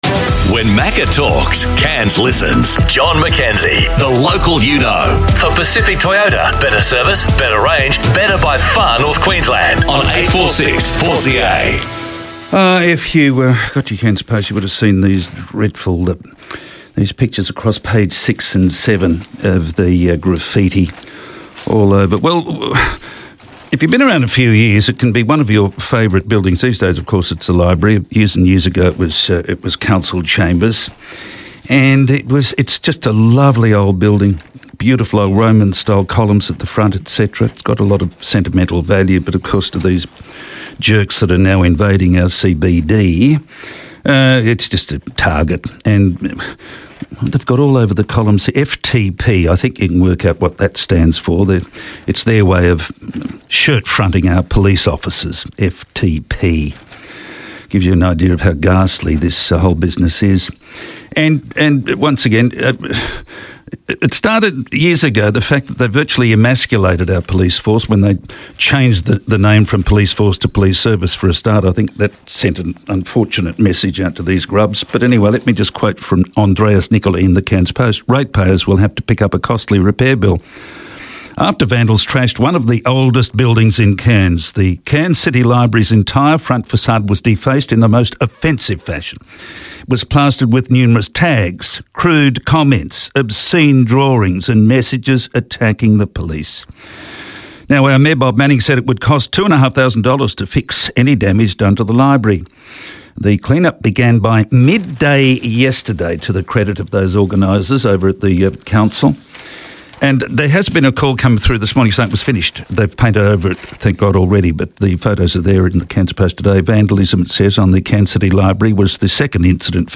Today I spoke with Bob Manning, Mayor of Cairns Regional Council, about crime in the CBD after the city's library was vandalised with graffiti.